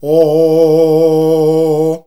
OOOOH   A#.wav